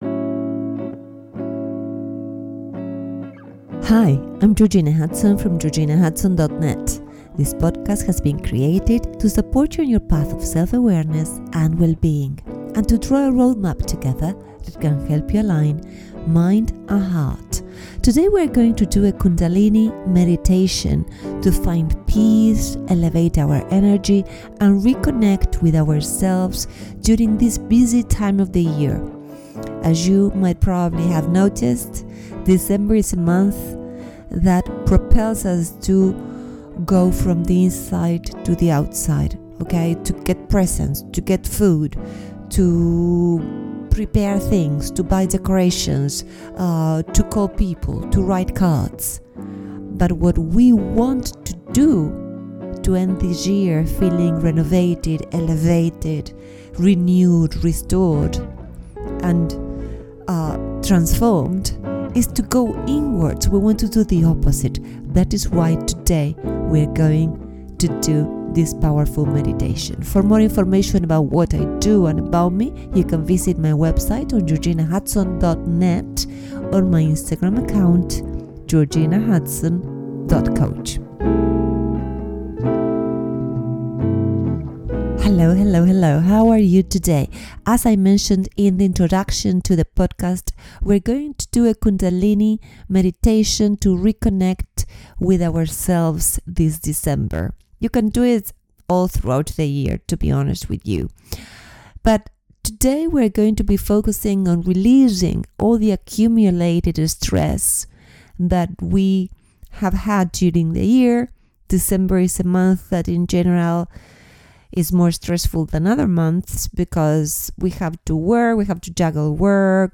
Kundalini Meditation to Reconnect with our deeper self